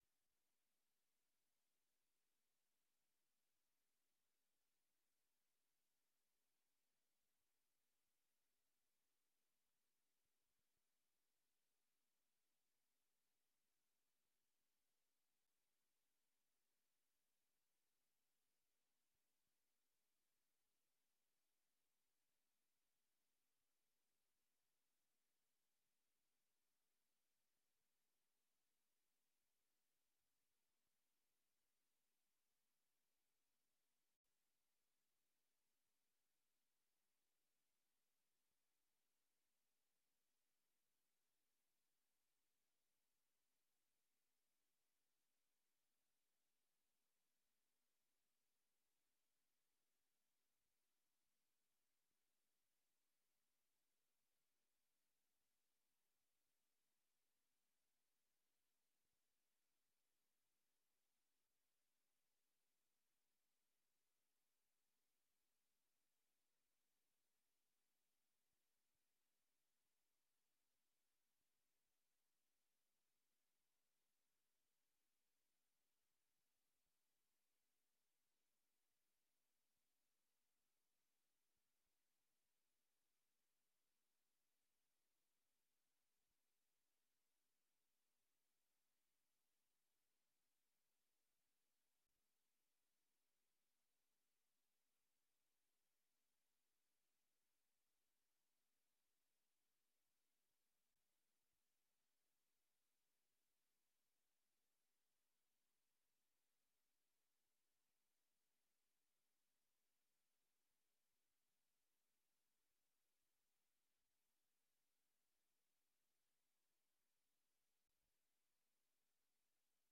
Beeldvormende vergadering 12 oktober 2023 19:30:00, Gemeente Dronten
Download de volledige audio van deze vergadering